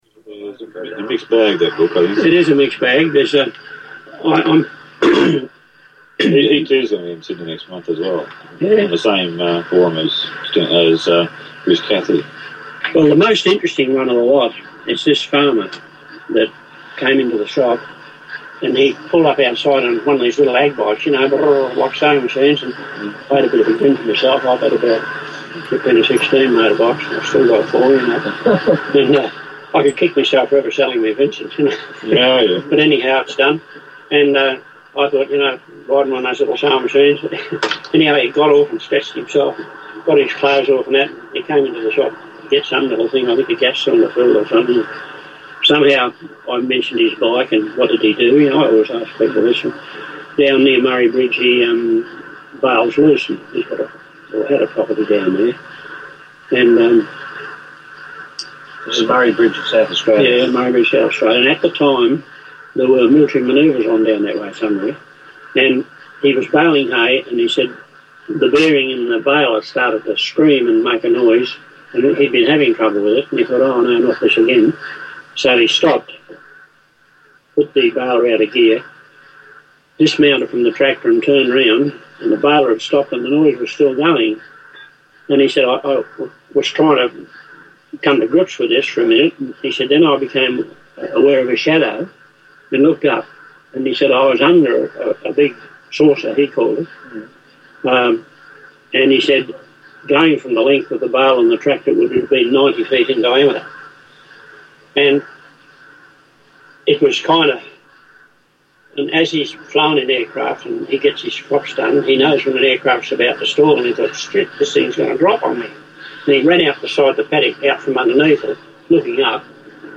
AUDIO ENHANCED